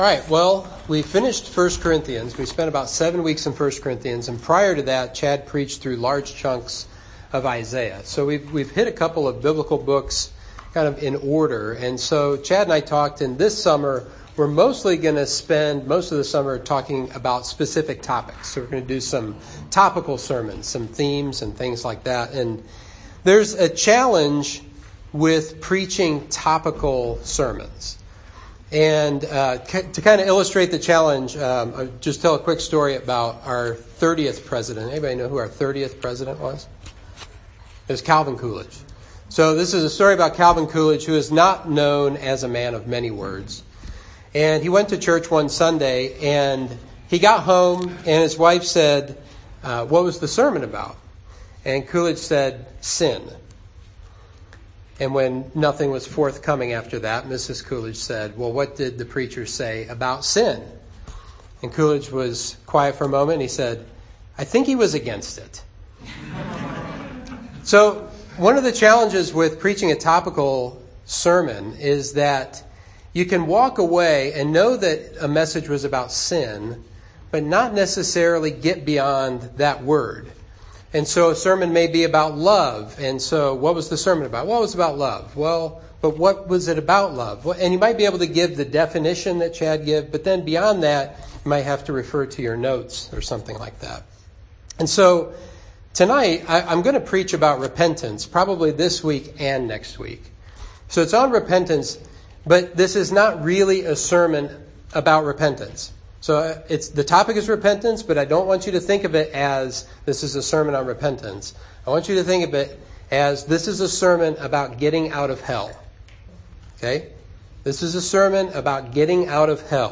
Sermon 6/4: Getting Out of Hell